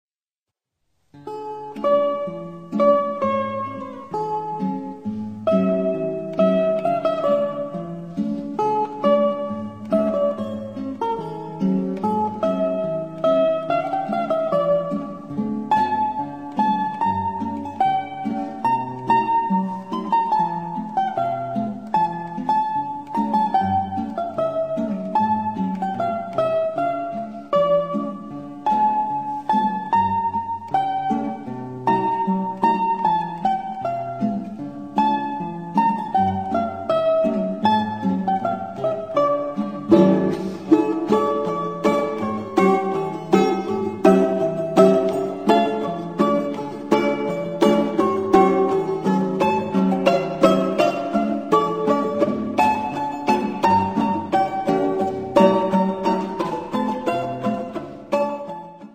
Русская музыка на балалайке в примерах